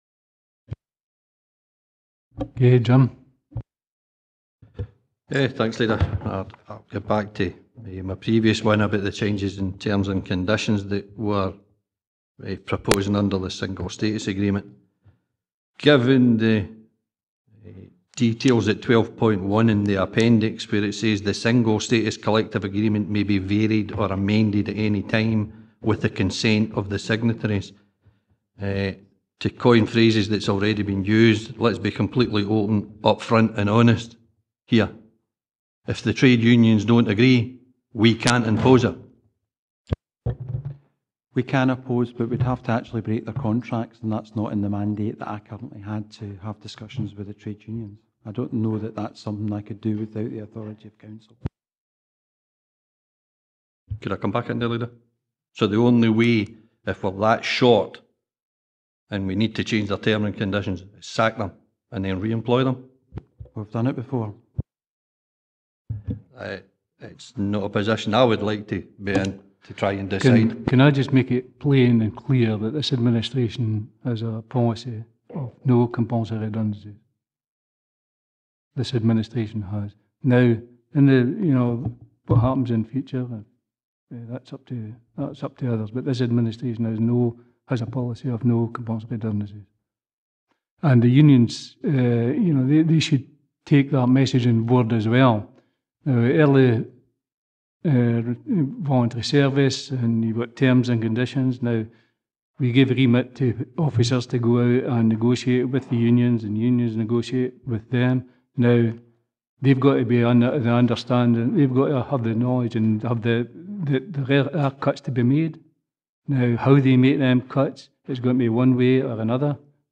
Yesterday the Council assured the employees there will be no redundancies or cuts to Terms & Conditions. Listen however, to the audio of Policy and Resources Committee on  15 September 2016.